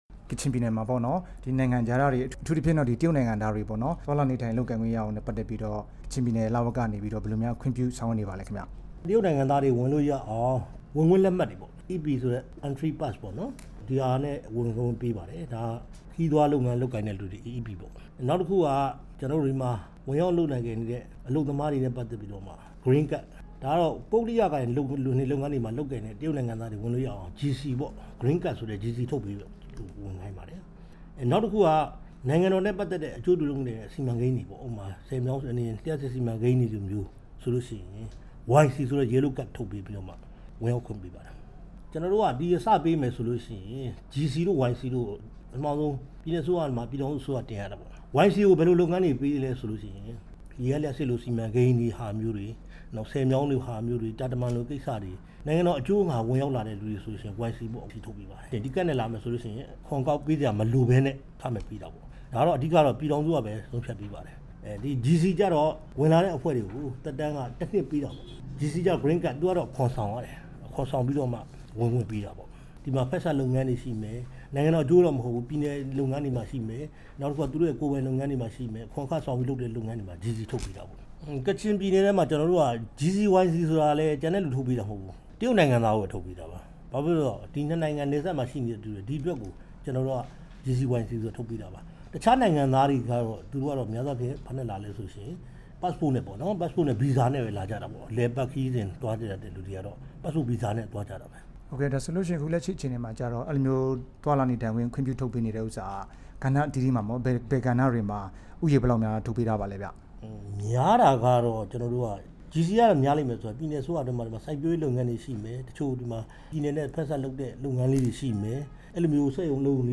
မြစ်ကြီးနားမြို့မှာ ဒီကနေ့ တွေ့ဆုံမေးမြန်းထားပါတယ်။